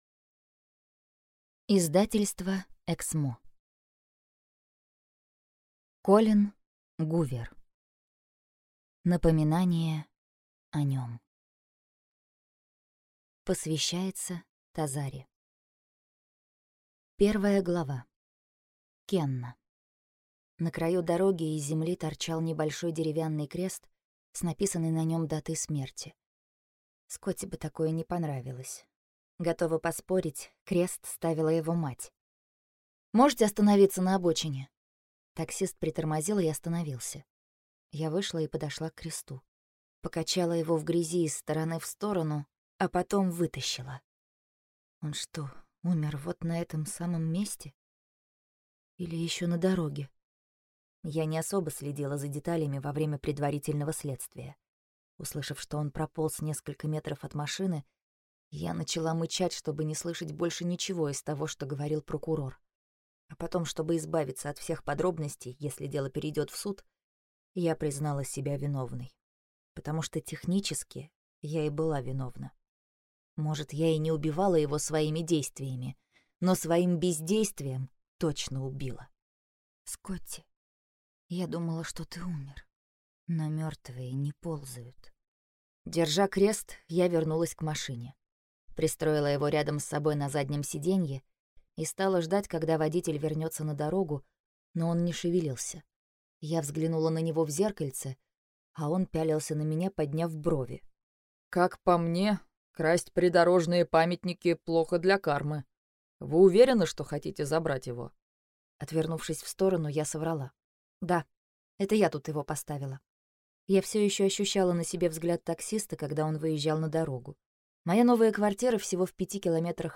Аудиокнига Напоминание о нем | Библиотека аудиокниг